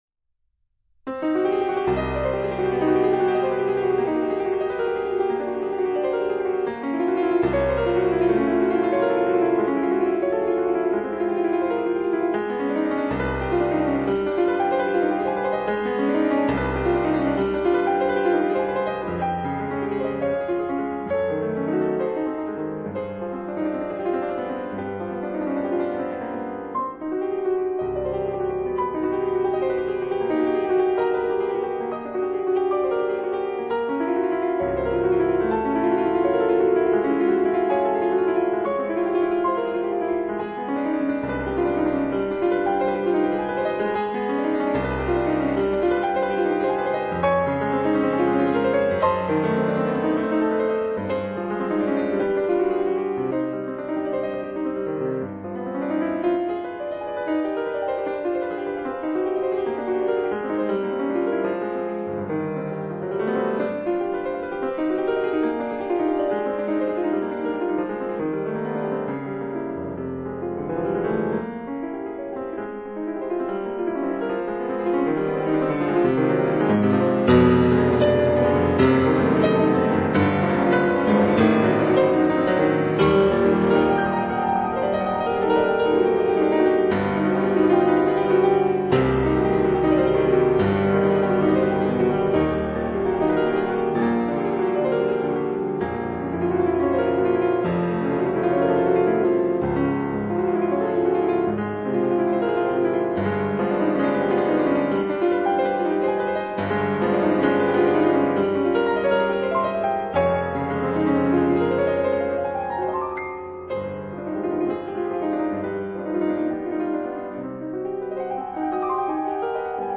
Piano Pieces